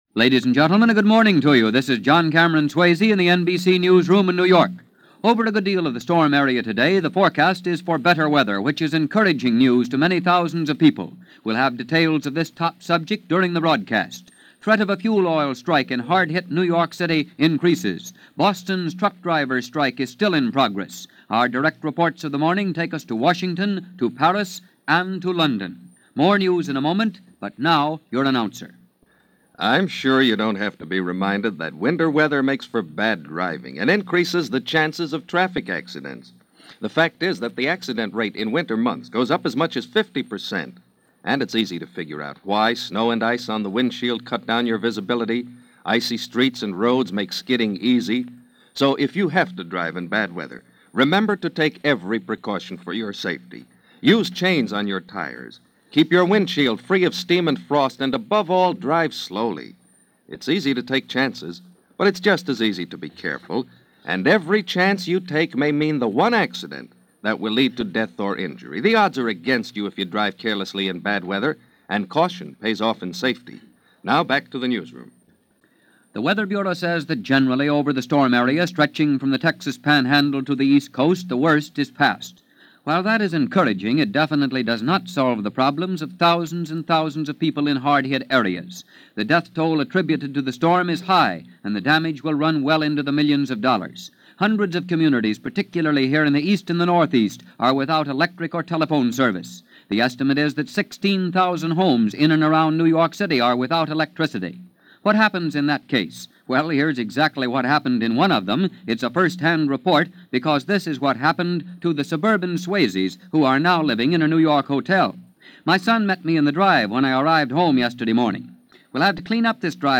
French Politics - American Weather - Icy Fingers - Icy Stares - January 3, 1948 - NBC Radio - News Of The World.